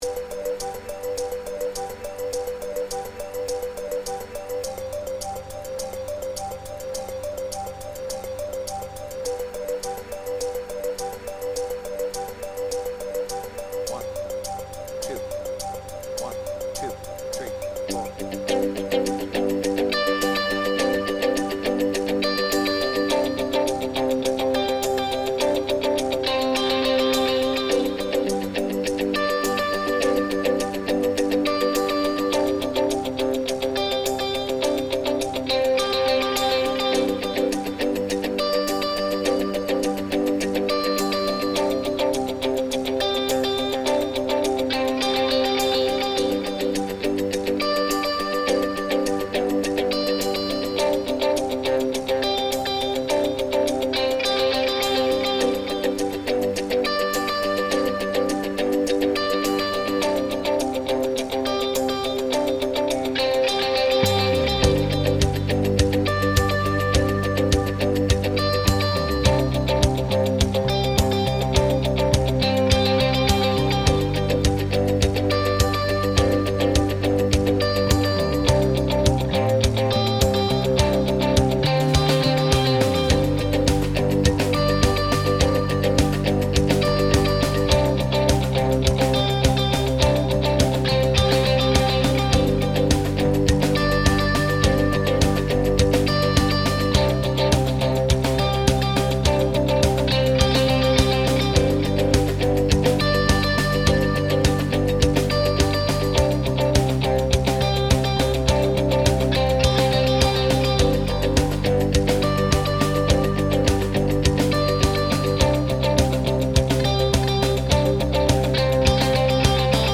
BPM : 104
Tuning : D
Without vocals
Mashup of different live version